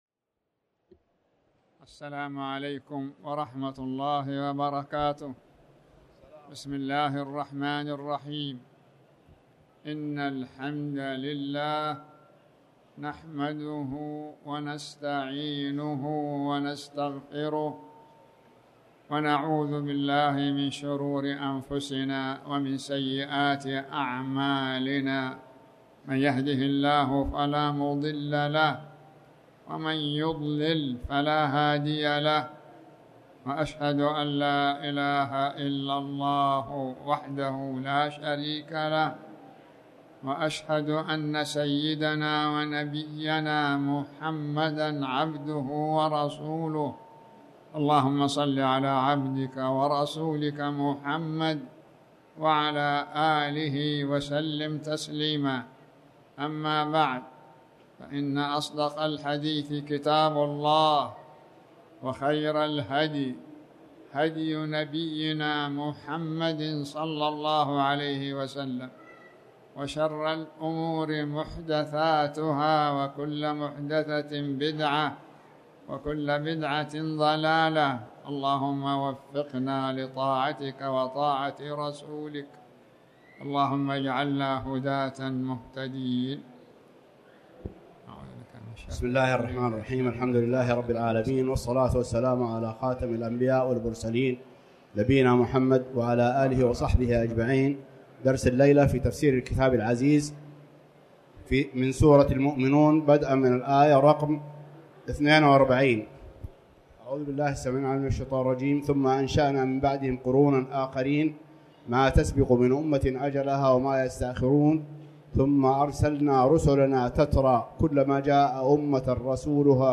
تاريخ النشر ٢٧ شوال ١٤٣٩ هـ المكان: المسجد الحرام الشيخ